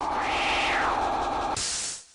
Whirlwind.mp3